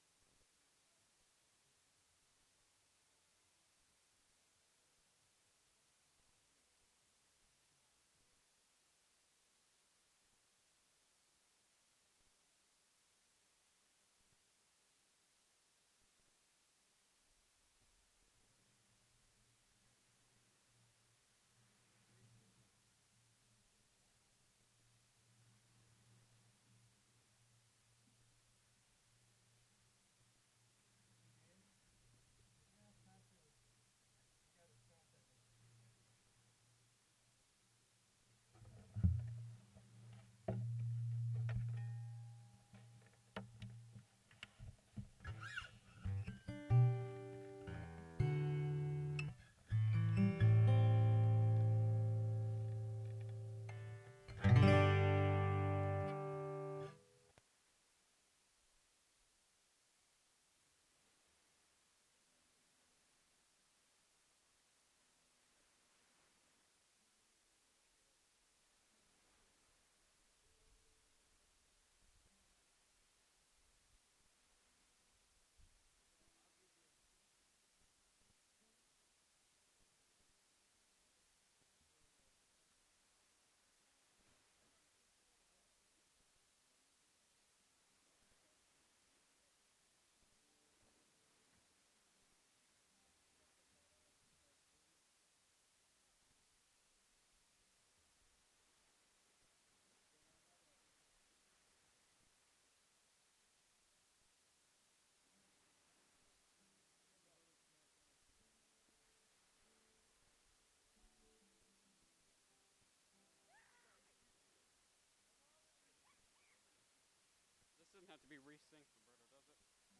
Download Download Reference Mark 8:14-29 Sermon Notes Click Here for Notes 250706.pdf SERMON DESCRIPTION We gather today on this holiday weekend to honor God and thank Him for all He has provided.